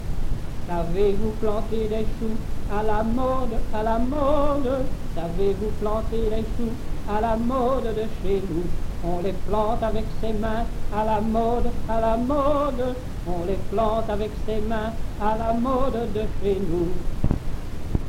Genre : chant
Type : chanson d'enfants
Lieu d'enregistrement : Verviers
Support : bande magnétique